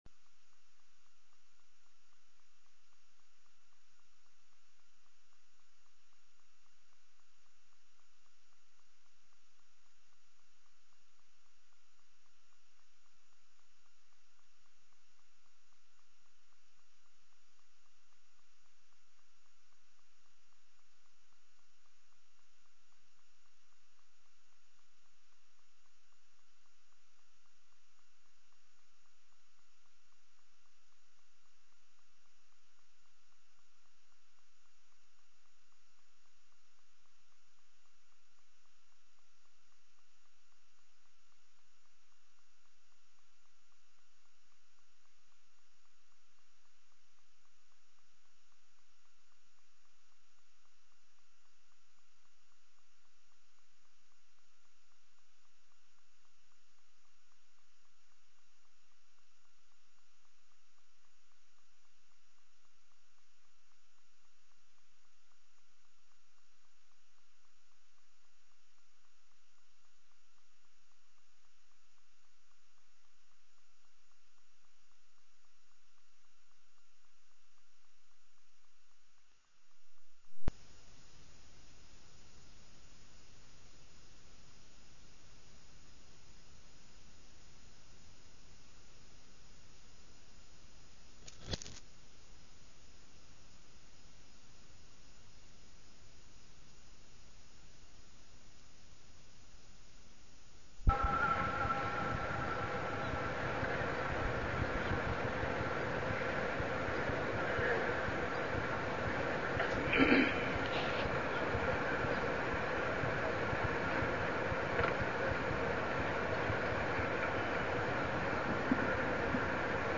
تاريخ النشر ٣ ذو القعدة ١٤١٩ هـ المكان: المسجد الحرام الشيخ: عمر السبيل عمر السبيل أبواب البر و الإحسان